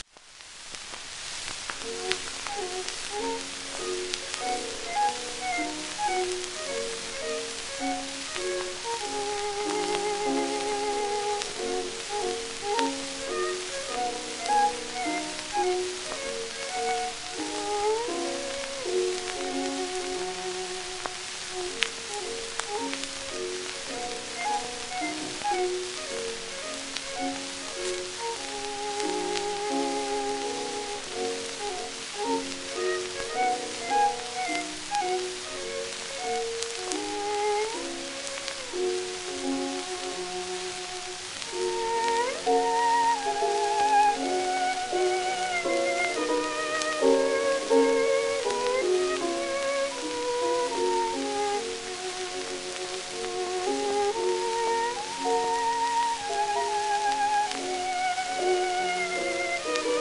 12インチ片面盤
盤質A-B+ *小キズ、薄いスレ
1922年ベルリンでの録音。
旧 旧吹込みの略、電気録音以前の機械式録音盤（ラッパ吹込み）